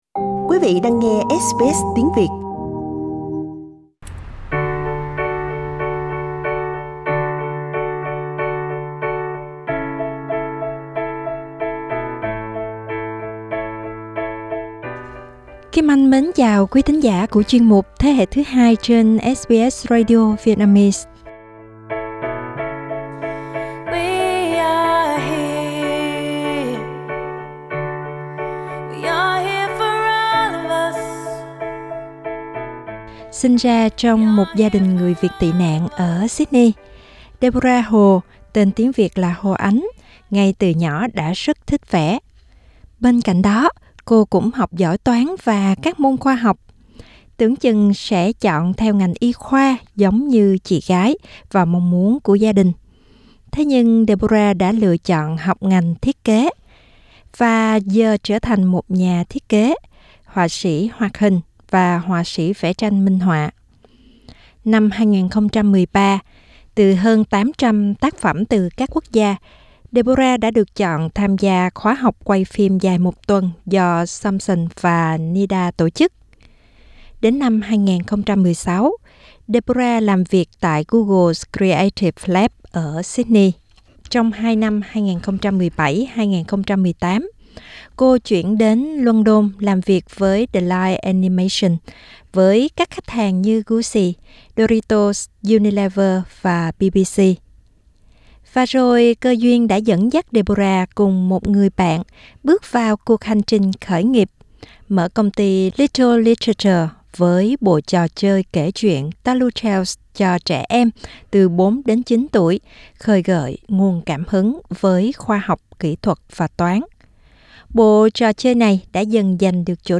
cuộc trò chuyện